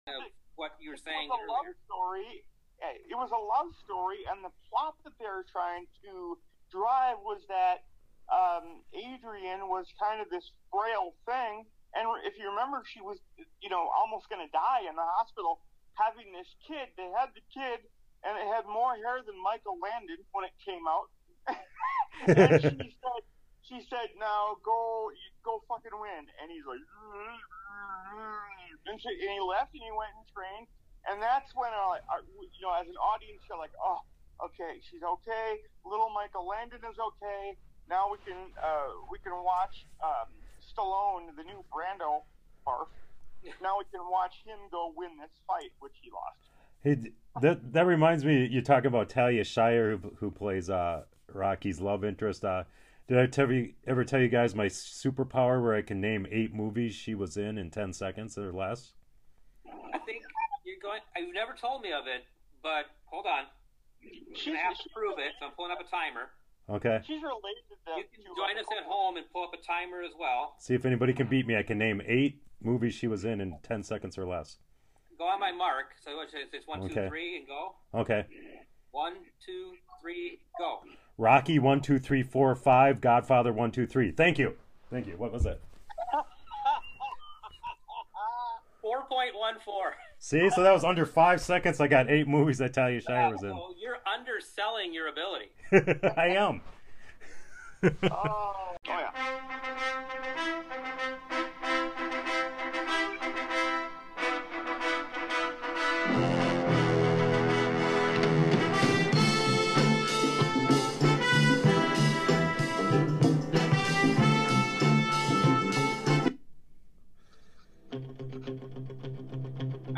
a hilarious Sly Stallone impression